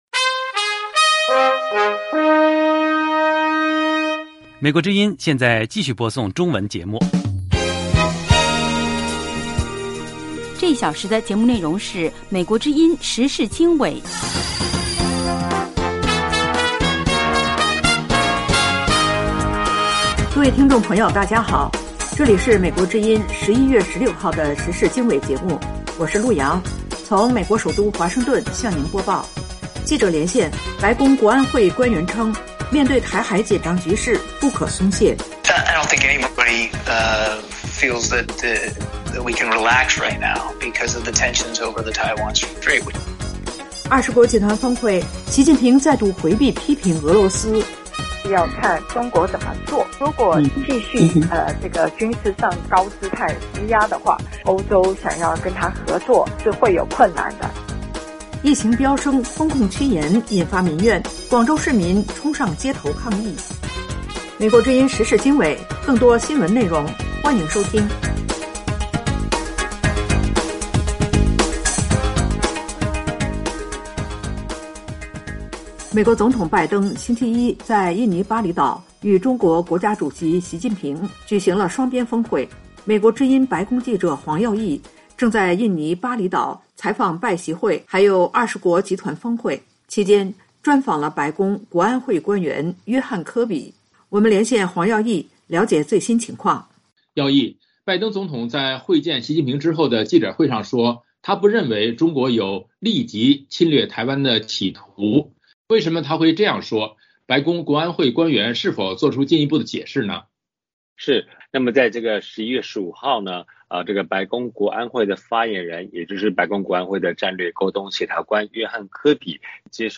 时事经纬(2022年11月16日)：1/记者连线：白宫国安会官员称面对台海紧张局势不可松懈。